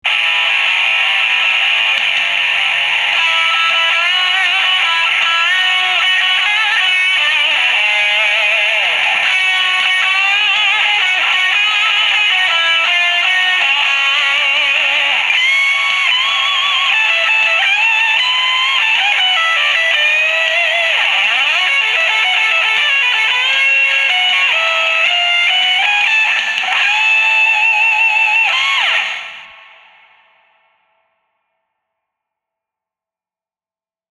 Flying-Tree-Gtr-Hall-Lim.mp3